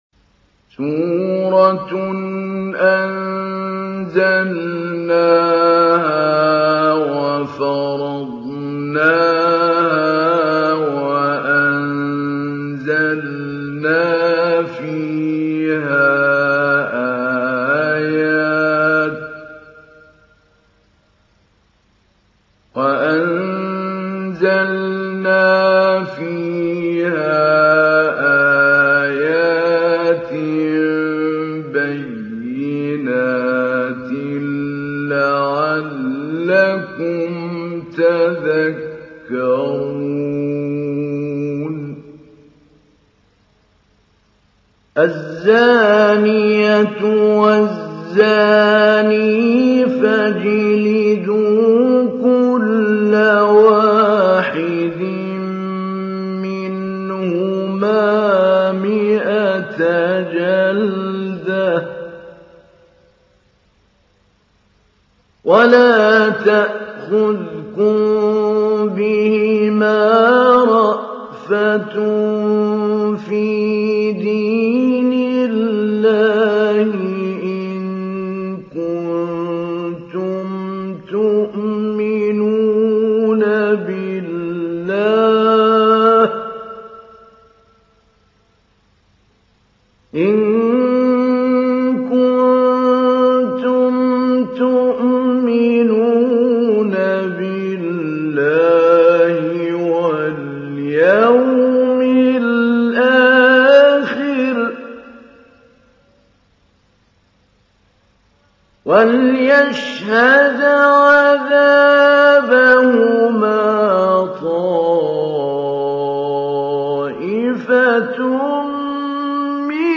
تحميل : 24. سورة النور / القارئ محمود علي البنا / القرآن الكريم / موقع يا حسين